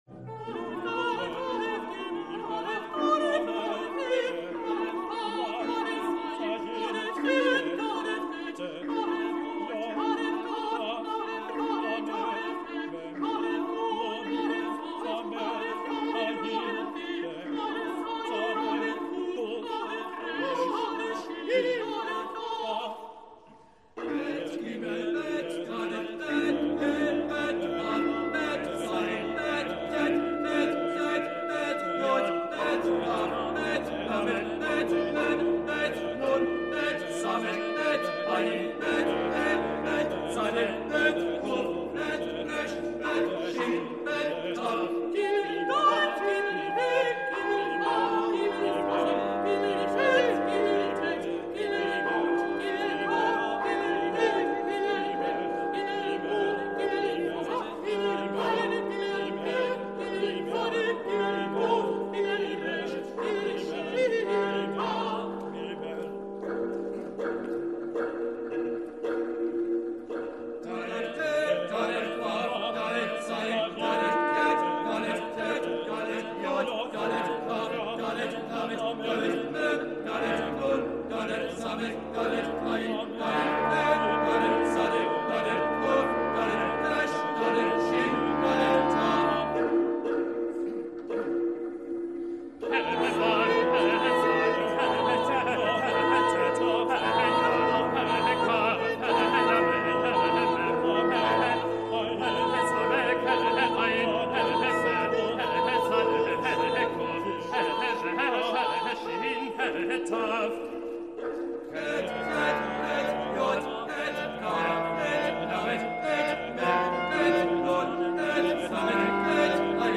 Oratorio with text in Hebrew